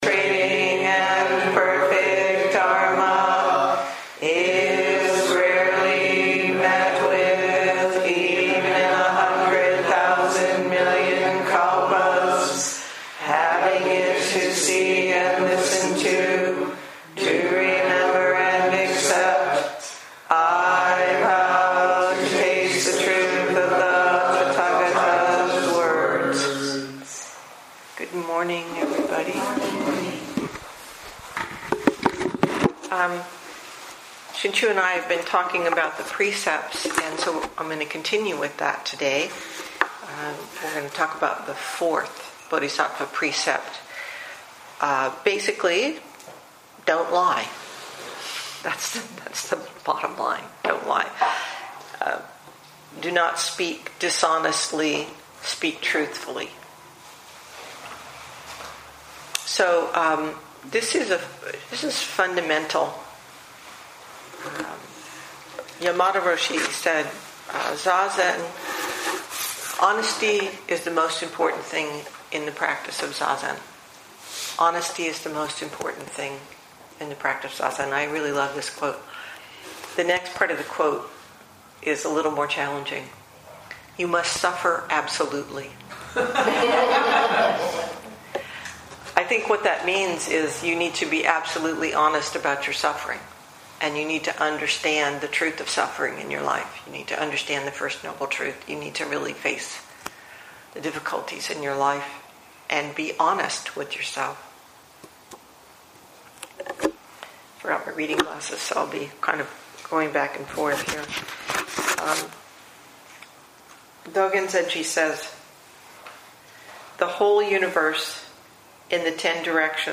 2017 in Dharma Talks